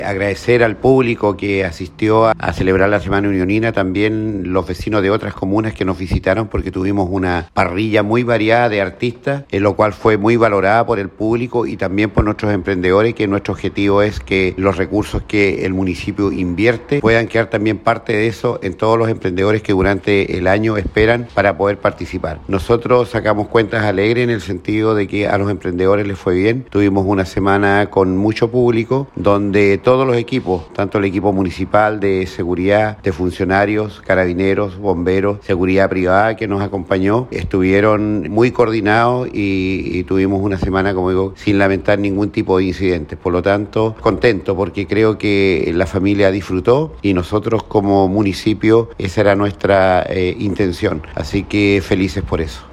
Alcalde-por-Semana-Unionina-2024.mp3